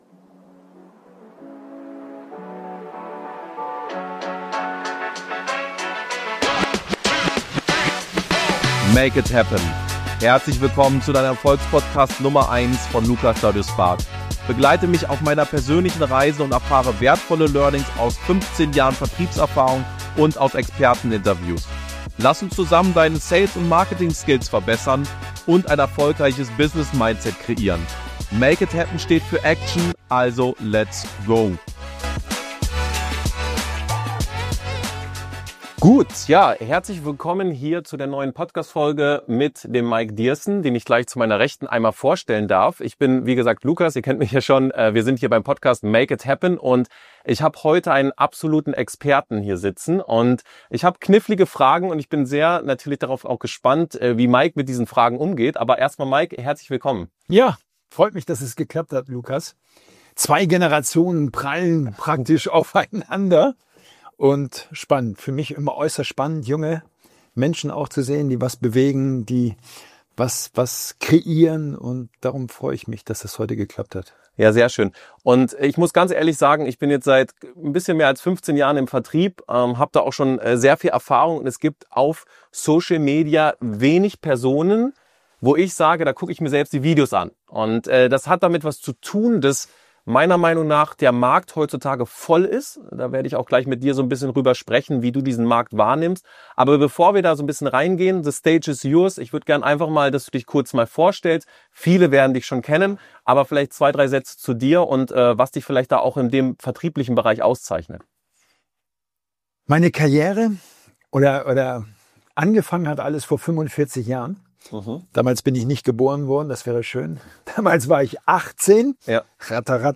Das große Interview